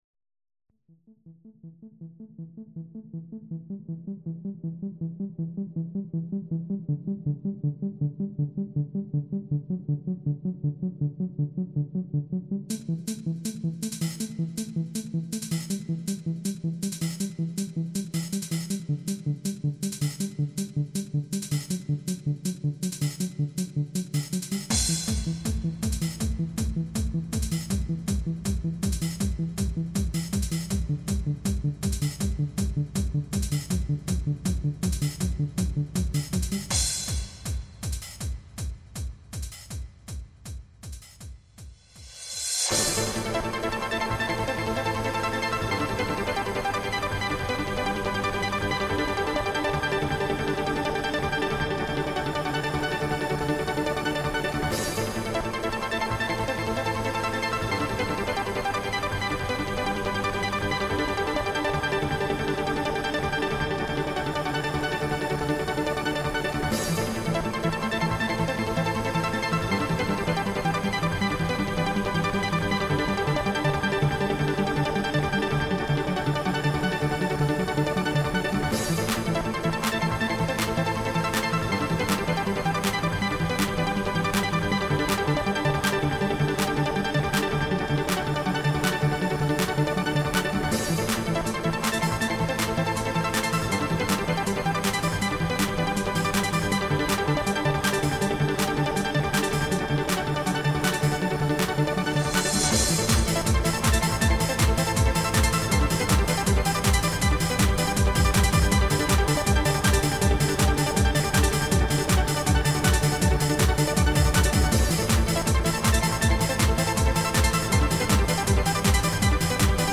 [remix]
• Jakość: 44kHz, Stereo